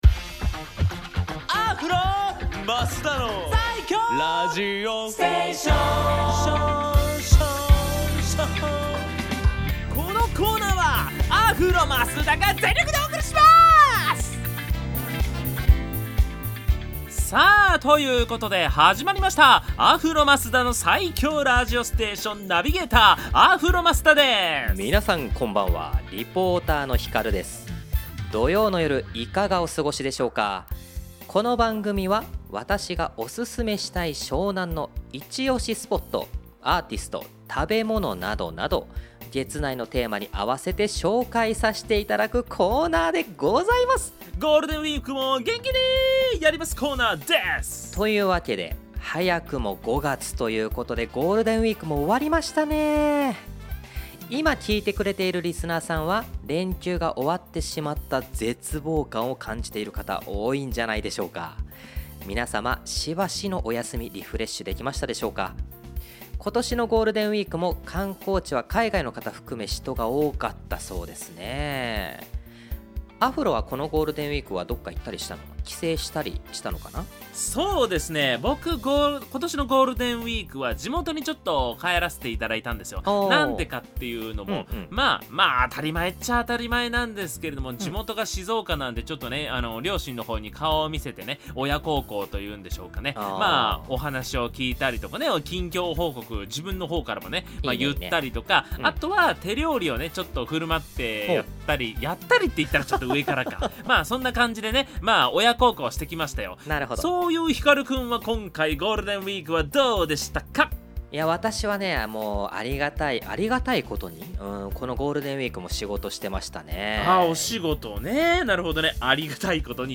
放送音源はこちら
こちらの放送音源をお届けします♪